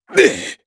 Chase-Vox_Landing_jp.wav